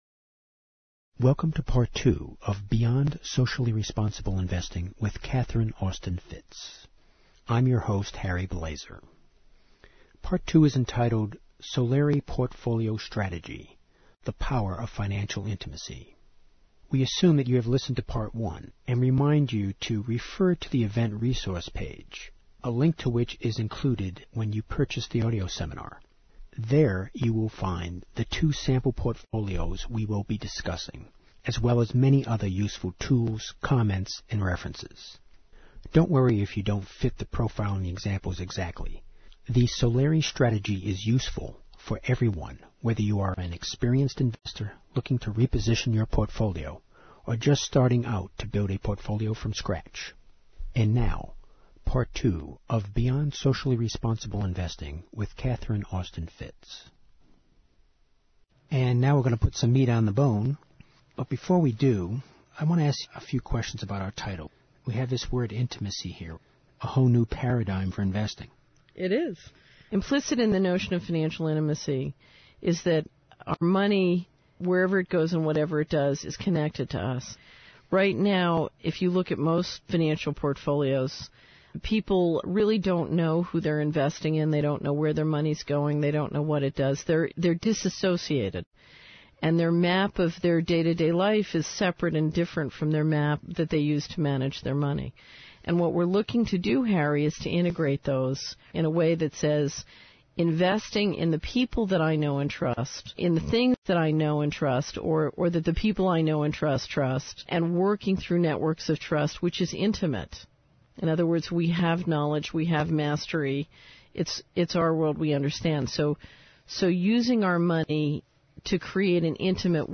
Each event ends with a brief invocation.